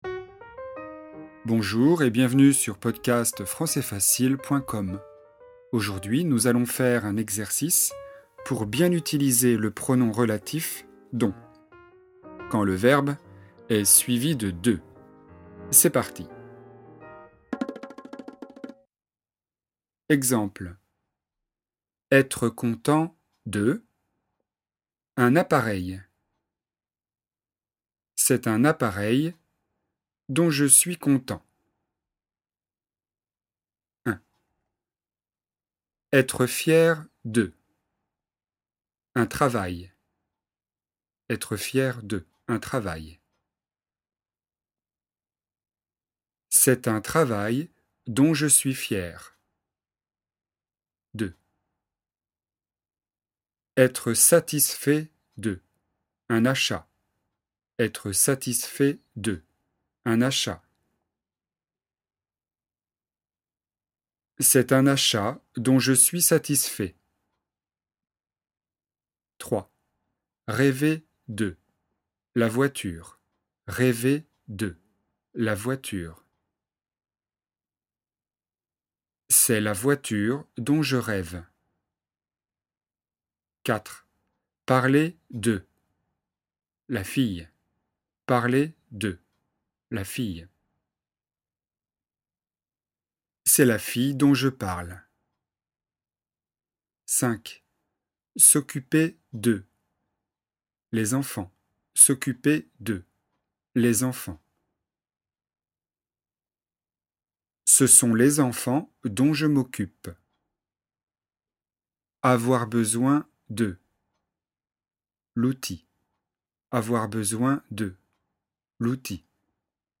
Exercice de grammaire, niveau intermédiaire (A2/B1) sur le thème du pronom relatif "dont".